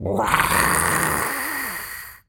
lizard_taunt_hiss_01.wav